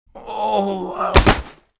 WAV · 155 KB · 單聲道 (1ch)